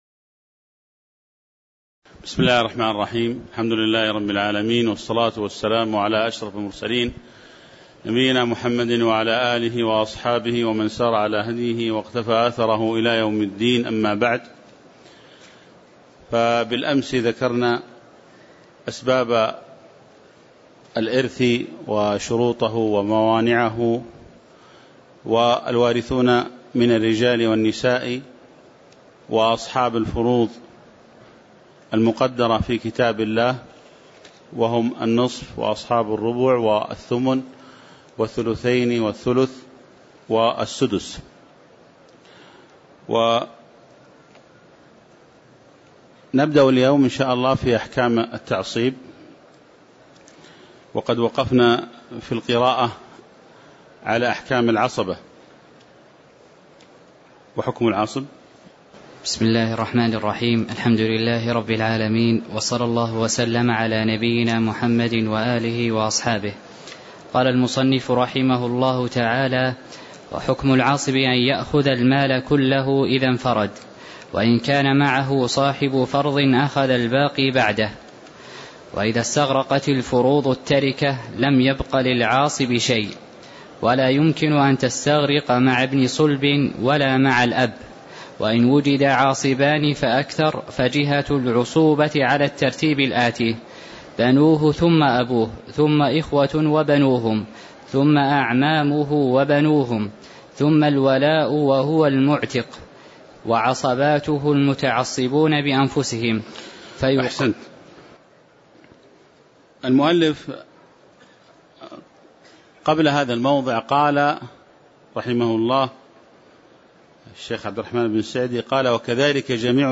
تاريخ النشر ٢٢ شوال ١٤٣٧ هـ المكان: المسجد النبوي الشيخ